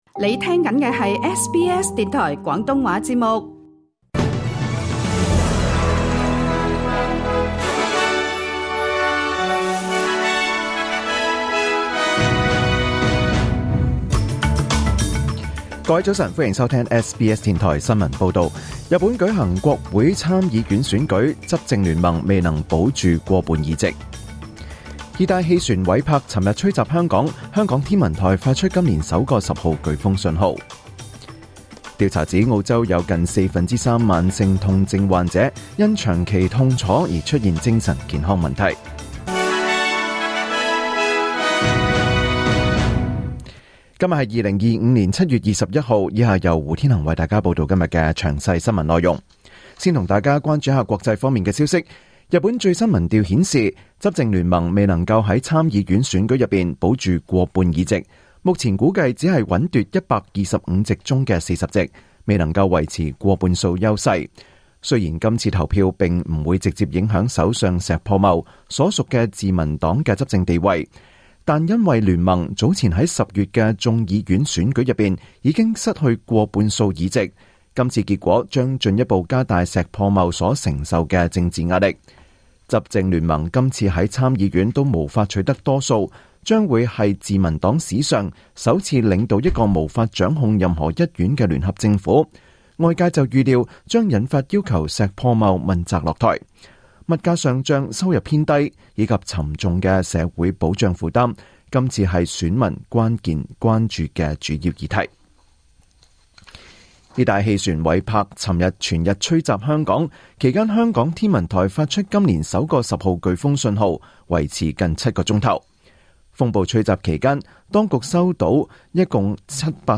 2025年7月21日SBS廣東話節目九點半新聞報道。